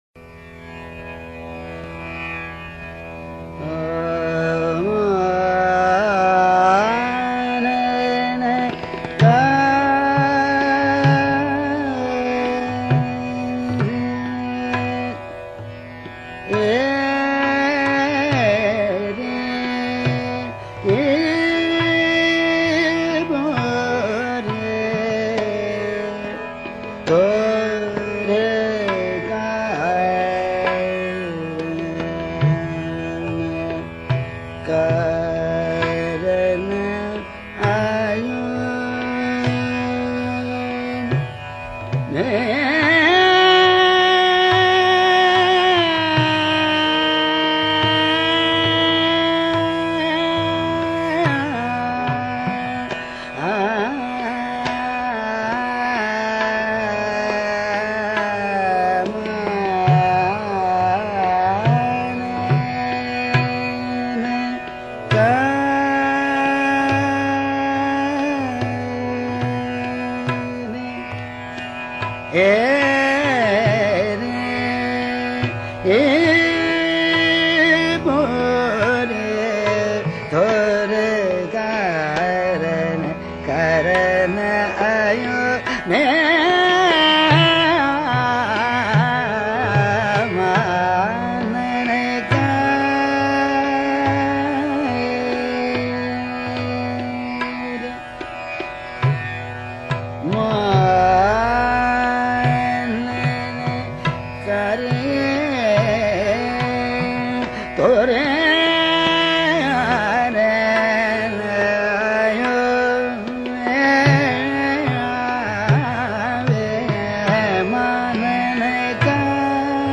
Mansur exudes a kind of magnetic pull towards the listener through his soulful but confident singing.